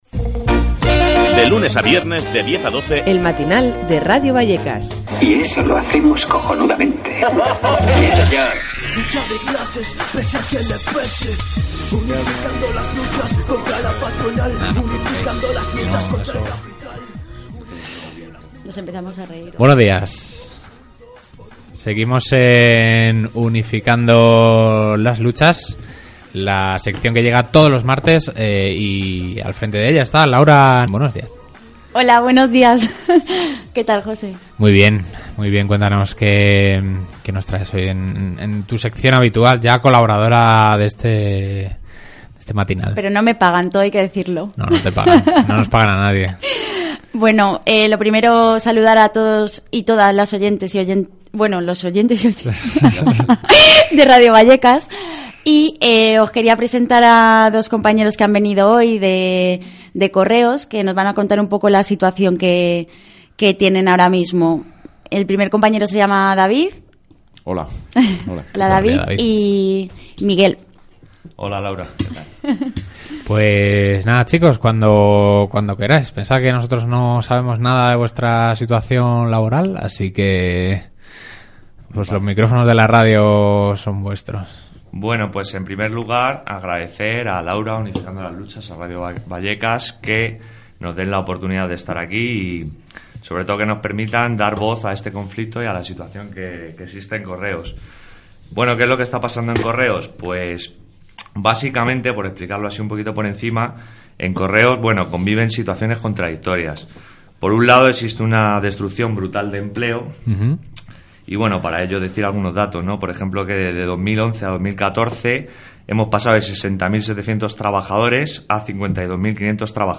Entrevista en Radio Vallekas sobre la situción de las carterias.